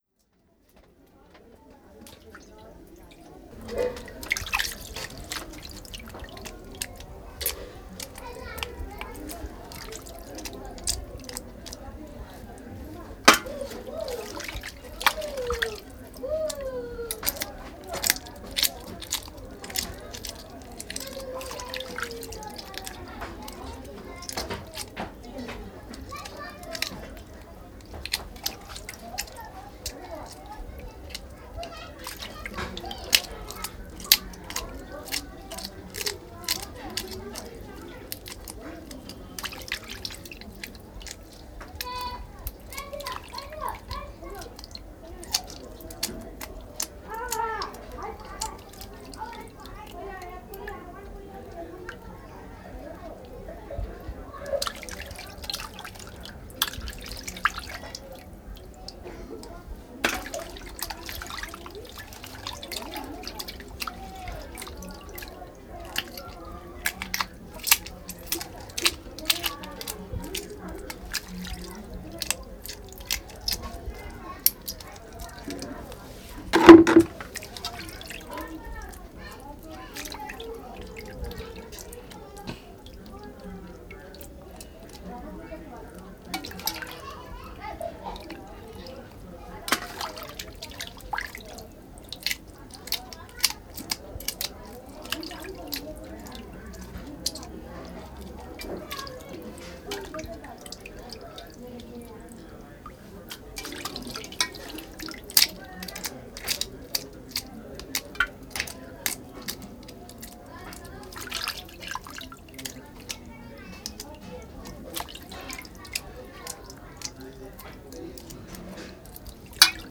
cozinhando o jantar